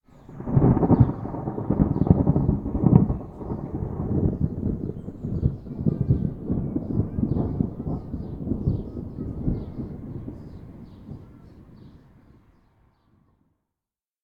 thunder_far_long.ogg